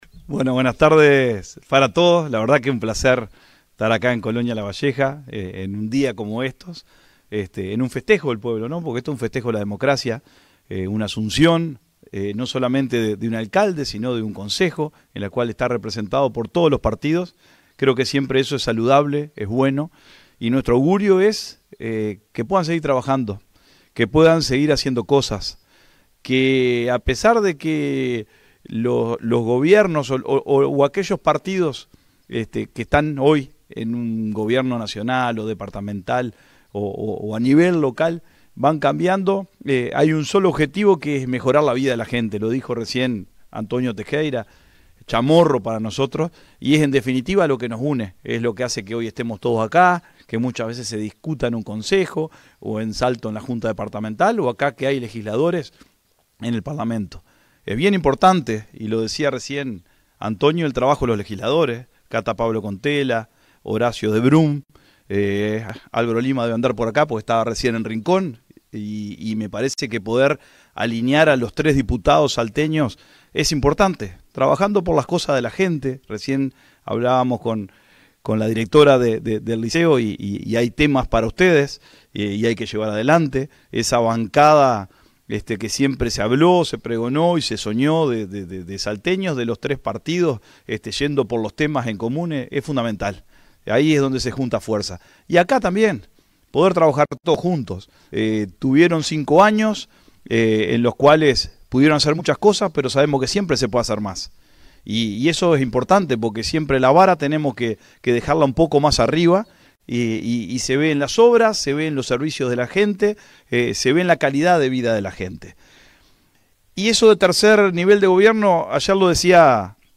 Este sábado se llevó a cabo la ceremonia de asunción de Antonio Tejeira como alcalde del Municipio de Colonia Lavalleja, en un acto que contó con la presencia del intendente de Salto, Dr. Carlos Albisu, junto a autoridades nacionales, departamentales y locales, vecinos y vecinas de la comunidad.